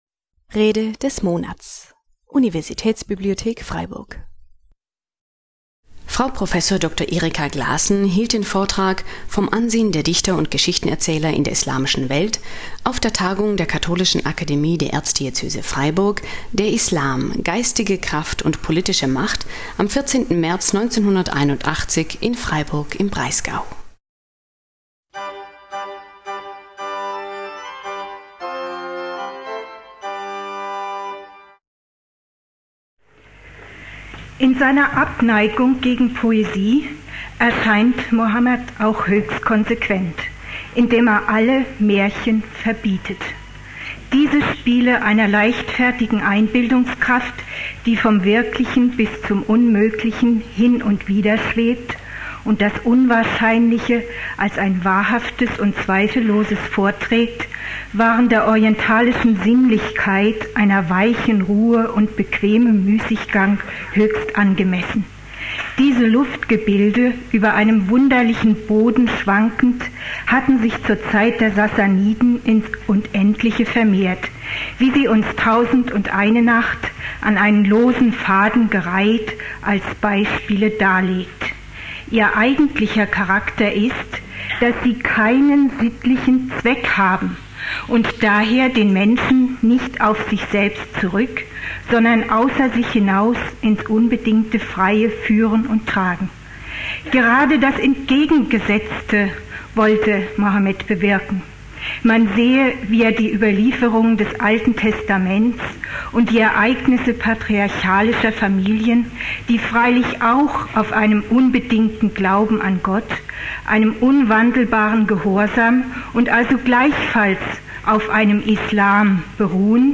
Vom Ansehen der Dichter und Geschichtenerzähler in der islamischen Welt (1981) - Rede des Monats - Religion und Theologie - Religion und Theologie - Kategorien - Videoportal Universität Freiburg